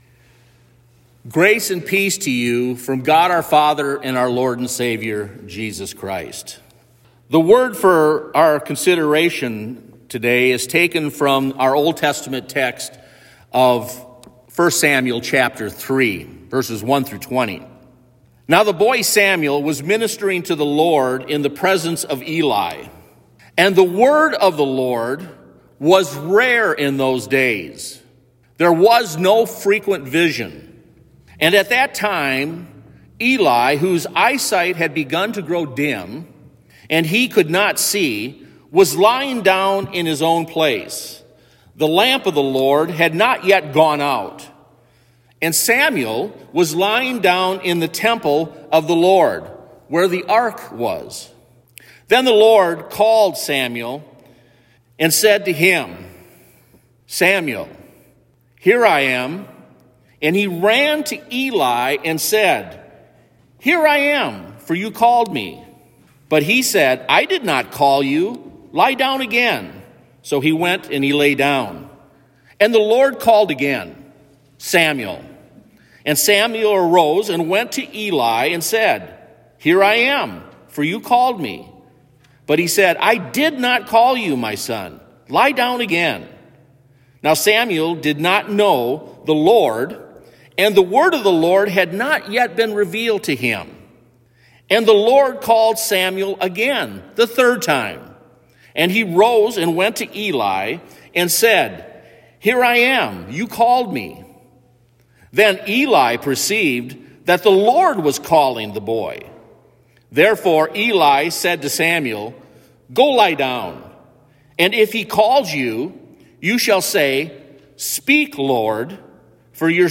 Sermon-second-Sunday-after-Epiphany.mp3